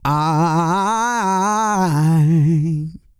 E-SOUL 320.wav